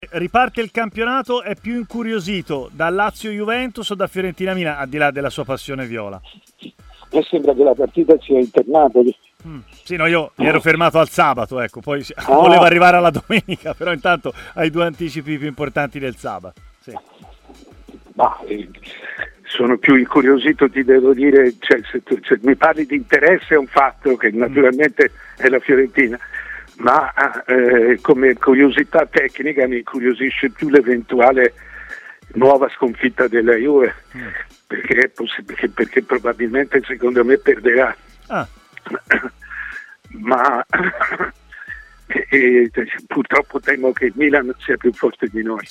Intervenuto ai microfoni di TMW Radio, Mario Sconcerti ha parlato, tra gli altri temi, di Lazio - Juventus di sabato pomeriggio.